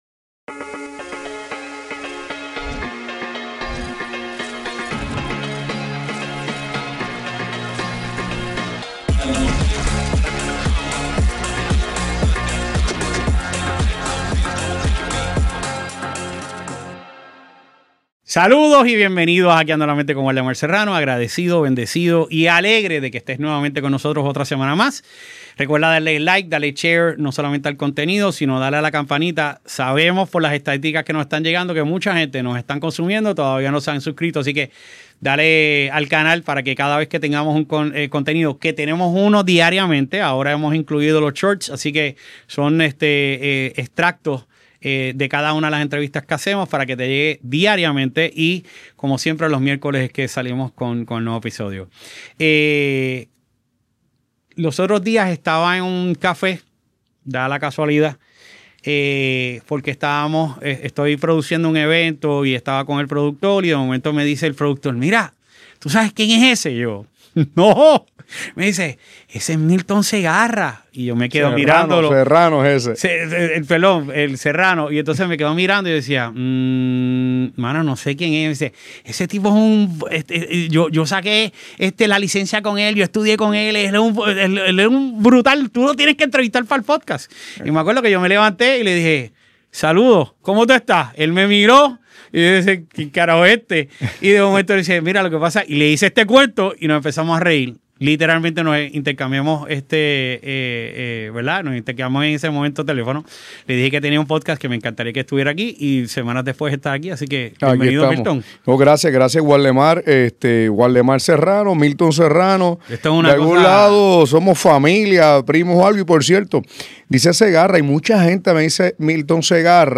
Una conversación cargada de visión, compromiso social y mucha inspiración para emprendedores, líderes y amantes del progreso.